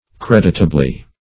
Creditably \Cred"it*a*bly\ (-?-bl?), adv.